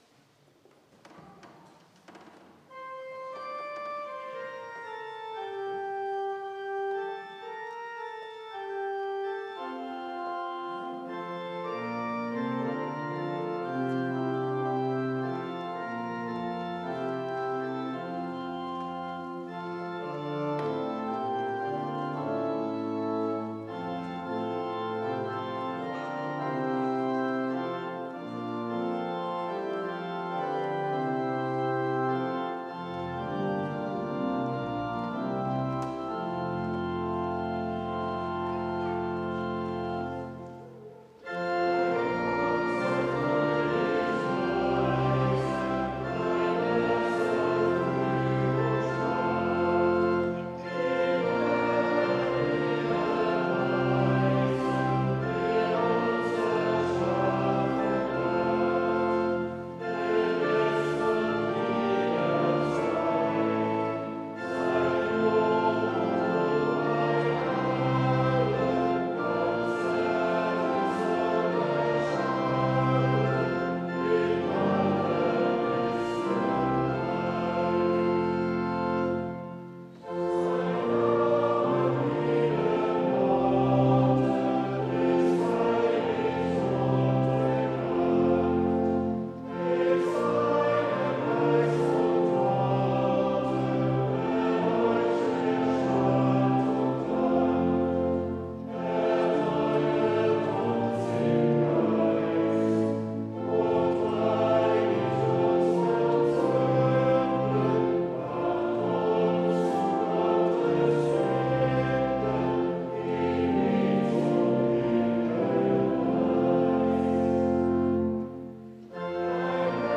Mein Mund soll fröhlich preisen... (LG 381) Evangelisch-Lutherische St. Johannesgemeinde Zwickau-Planitz
Audiomitschnitt unseres Gottesdienstes am 8. Sonntag nach Trinitatis 2023